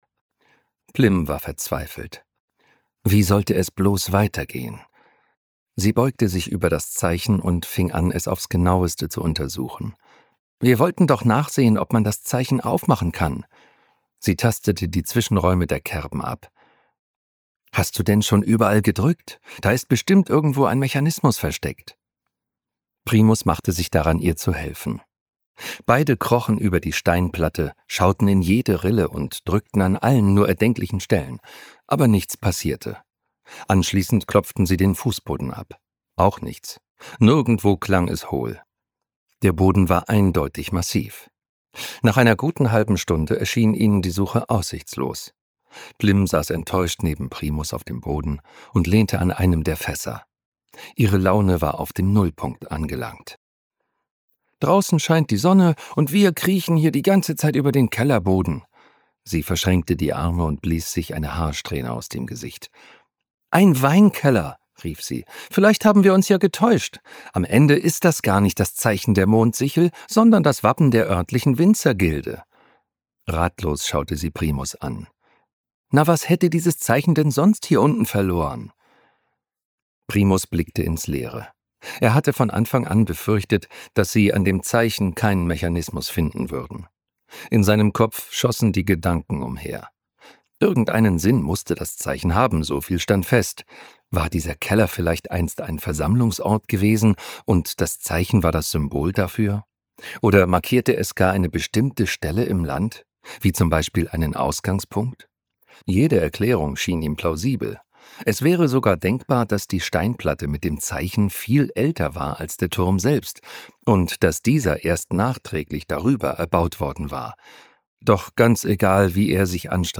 Das Unkrautland - Hörbuch